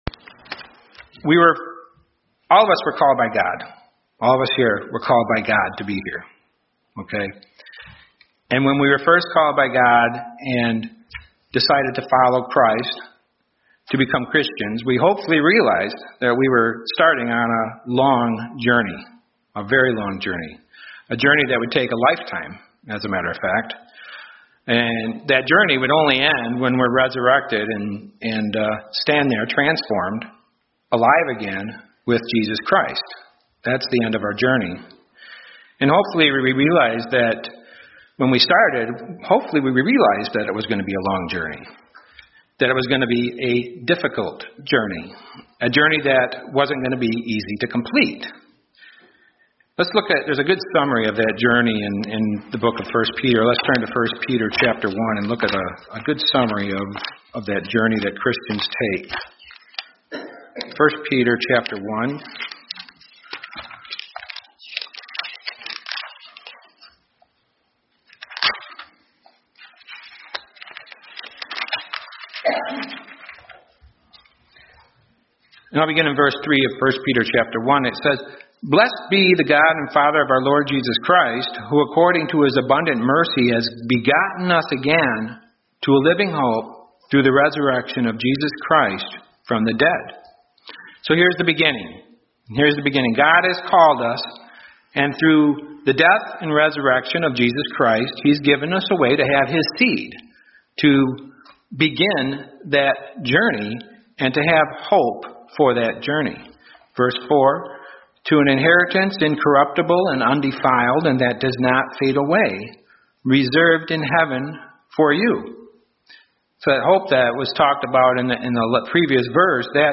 Given in Grand Rapids, MI
UCG Sermon Studying the bible?